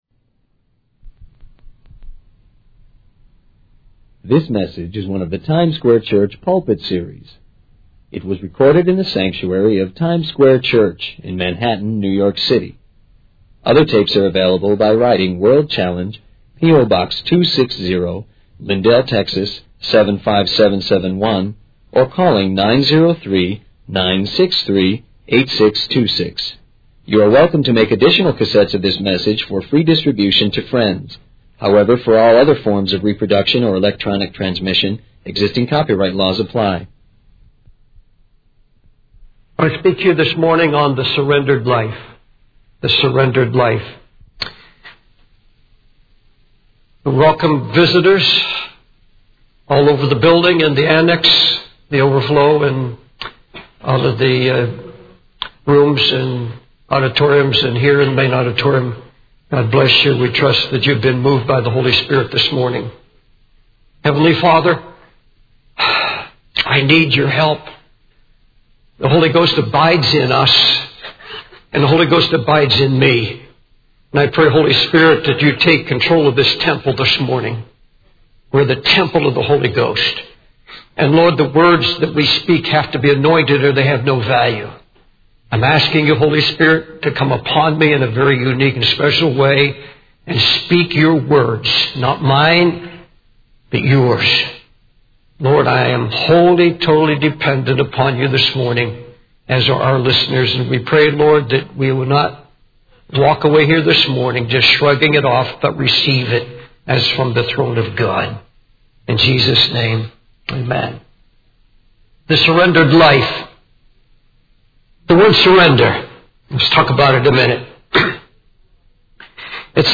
In this sermon, the preacher discusses the story of Saul's conversion on the road to Damascus.